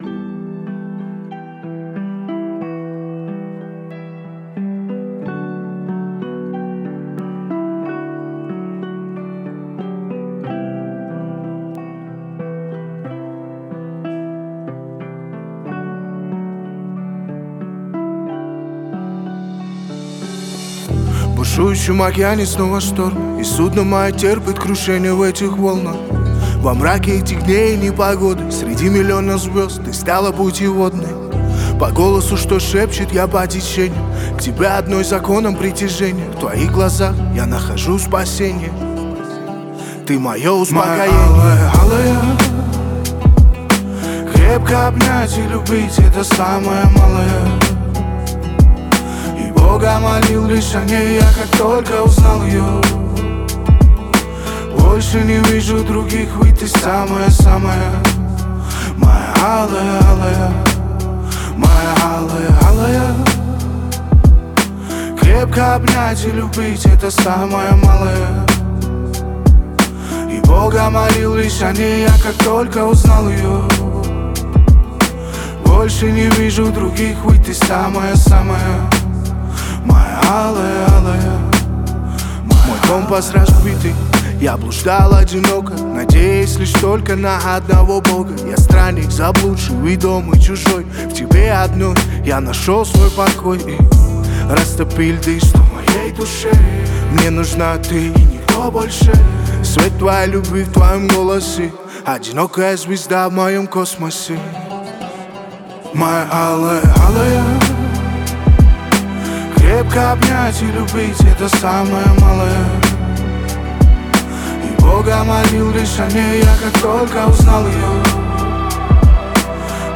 Красивые лиричные песенки
лиричные песни